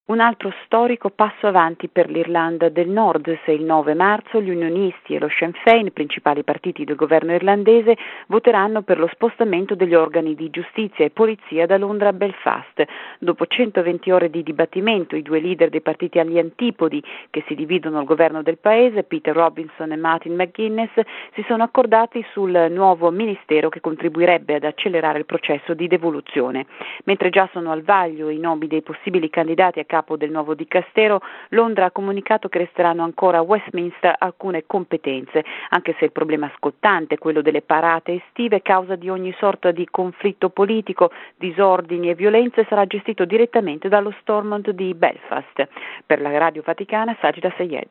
Da Londra, il servizio